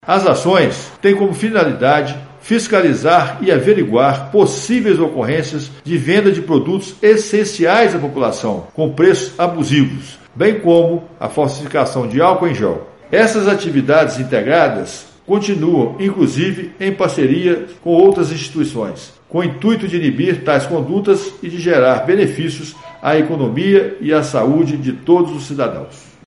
delegado-geral Gustavo Adélio Lara Ferreira